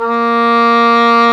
Index of /90_sSampleCDs/Roland LCDP04 Orchestral Winds/WND_English Horn/WND_Eng Horn 2